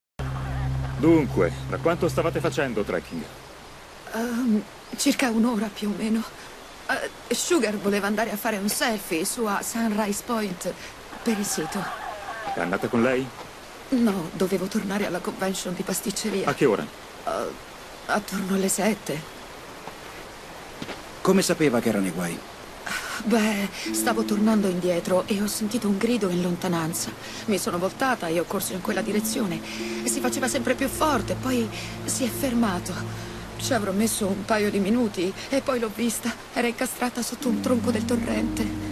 nel film TV "Le indagini di Ruby Herring -Testimone silenzioso", in cui doppia Jovanna Burke.